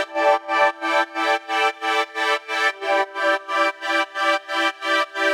GnS_Pad-MiscB1:8_90-E.wav